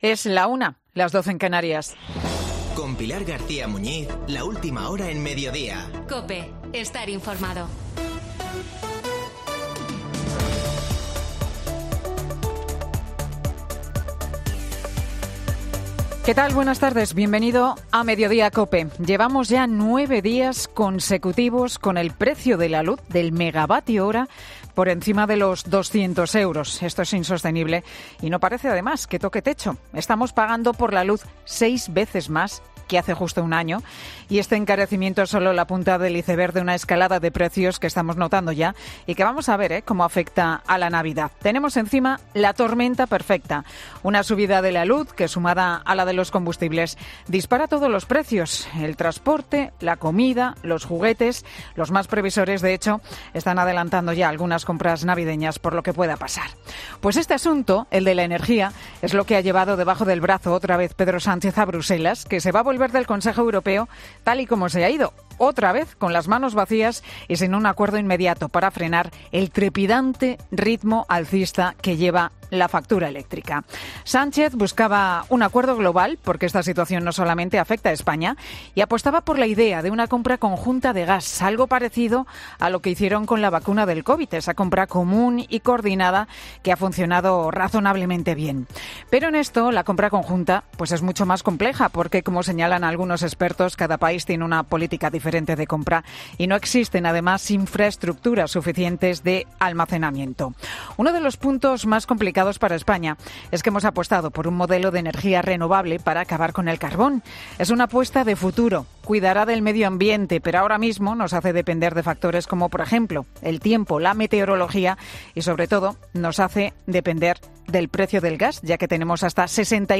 El Monólogo de Pilar García Muñiz en Mediodía COPE